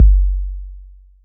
Kick 808 12.wav